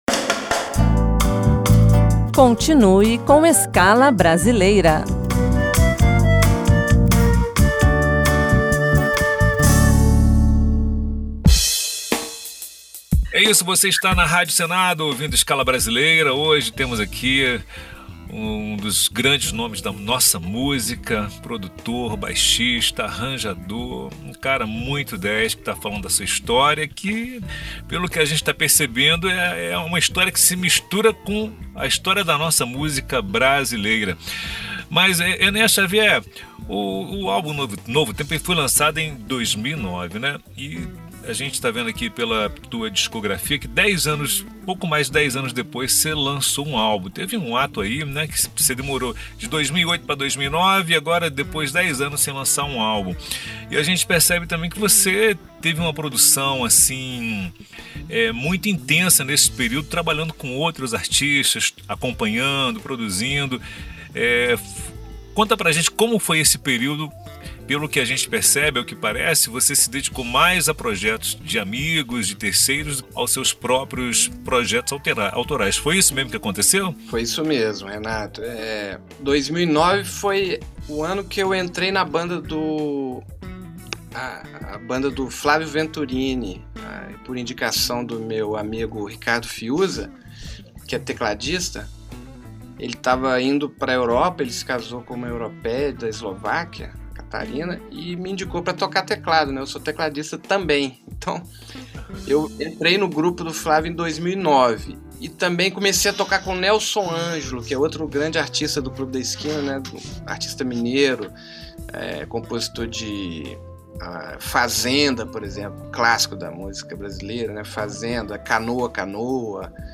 baixista e multi-instrumentista - Parte II